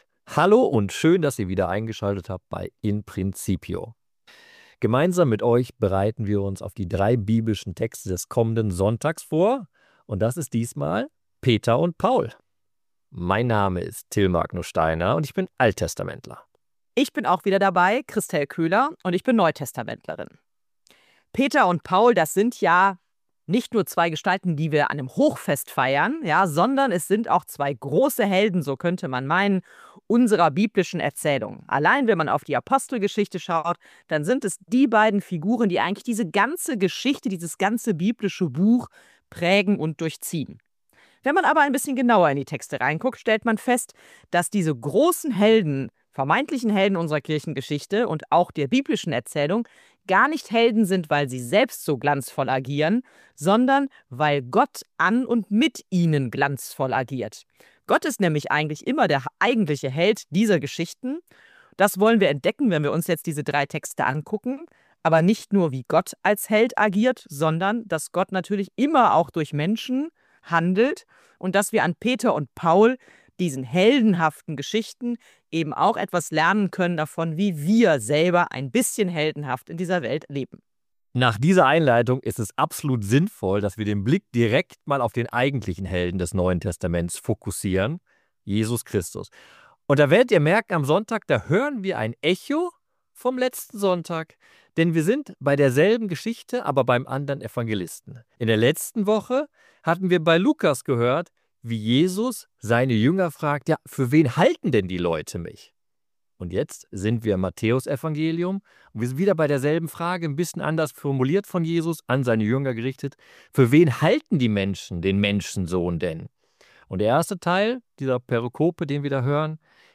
Diskussion